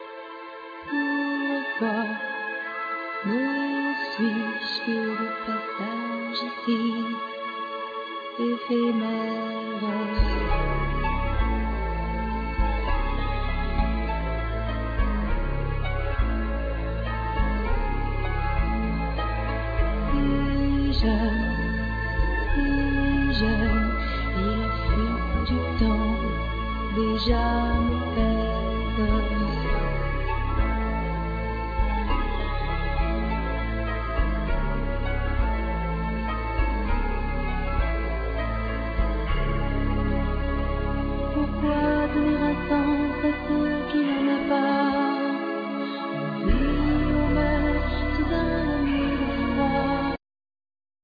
Violin,Viola,Guitar,Vocals
Keyboards,Backing vocals
Drums,Percussions
Ac.Guitar,Bass
English horn
Cello
Flute